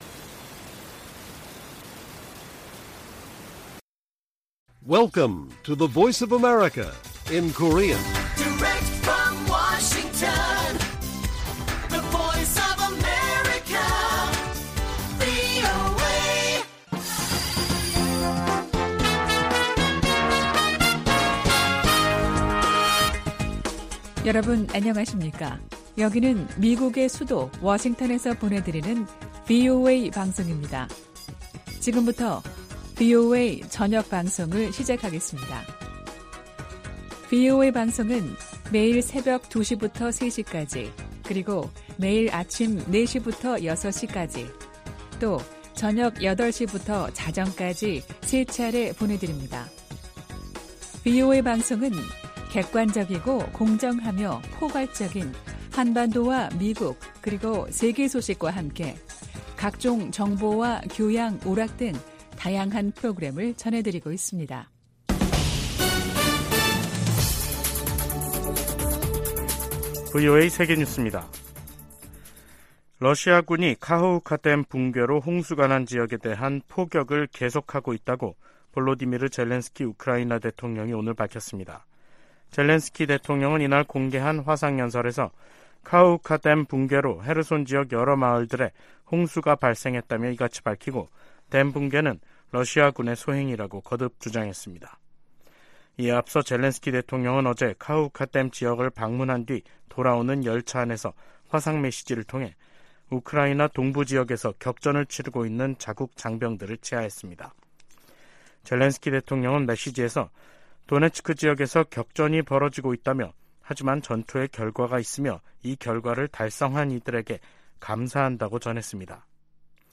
VOA 한국어 간판 뉴스 프로그램 '뉴스 투데이', 2023년 6월 9일 1부 방송입니다. 미 국무부가 한국의 새 국가안보전략을 환영한다고 밝혔습니다. 중국과 북한의 핵무력 증강에 대응해 미국도 핵전력을 현대화하고 있다고 국방부 고위 관리가 밝혔습니다. 북한이 군사정찰위성 발사 실패 이후 국제사회 비판 여론에 예민하게 반응하면서 주민들에겐 알리지 않고 있습니다.